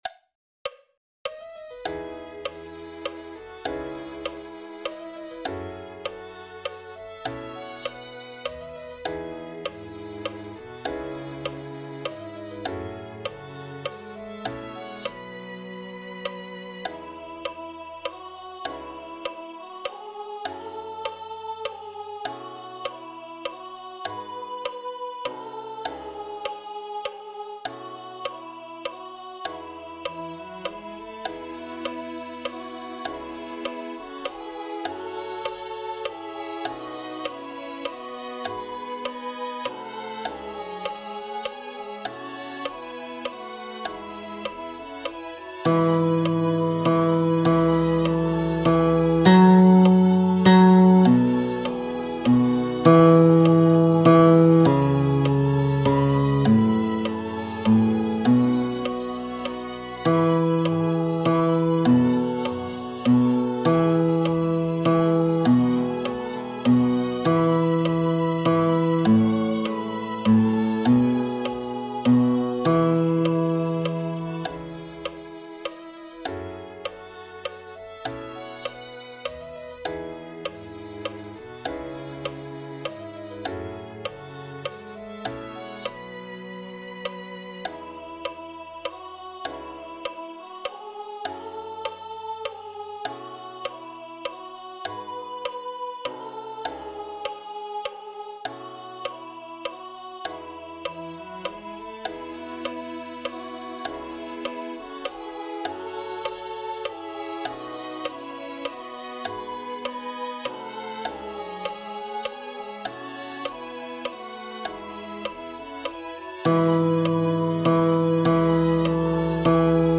This page contains recordings of the notes for the bass voice parts for the song that will be presented during the Christmas Sunday service (December 21, 2025).
Note that blank measures for the parts are not skipped; it follows the music as written so if you hear silence that's because there's nothing written for your part in that portion of the recording (i.e., your device isn't broken and your ears still work).
Come_Thou_Long_Expected_Jesus.E.BassLoud.mp3